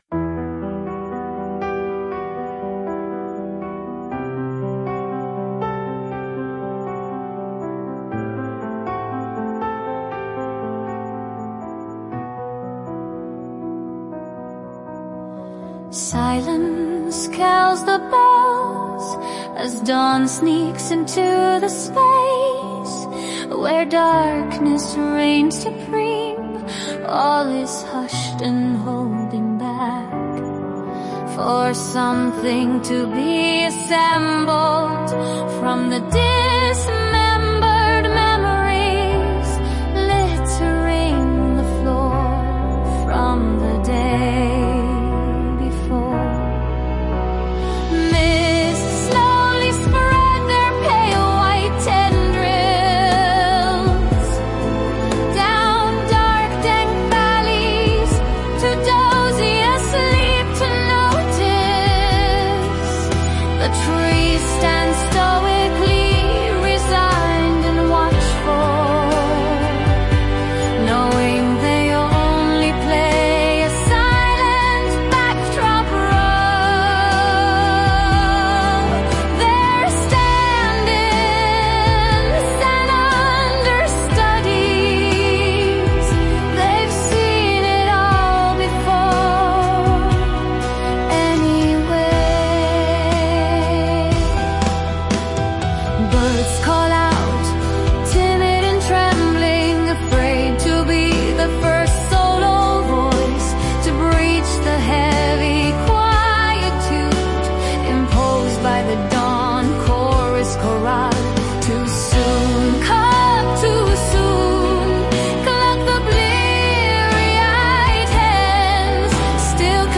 The musical accompaniment adds to it a lot.